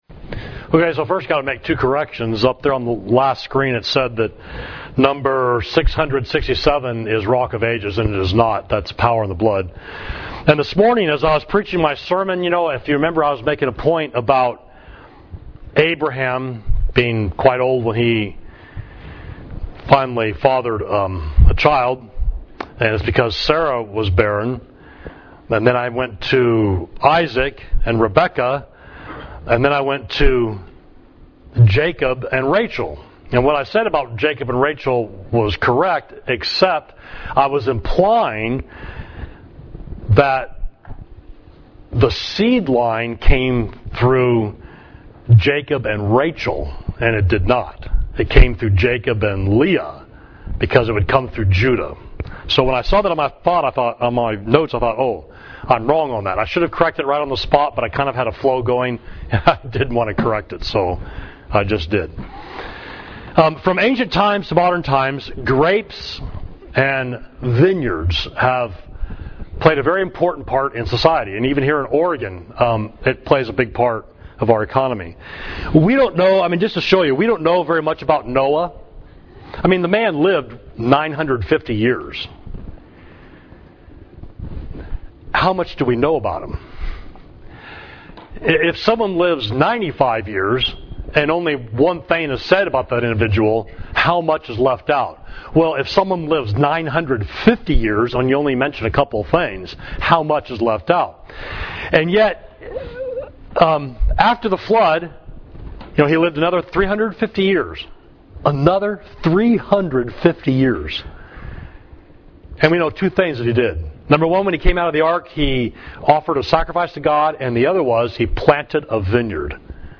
Sermon: The Bible and Food, Part 3 – Savage Street Church of Christ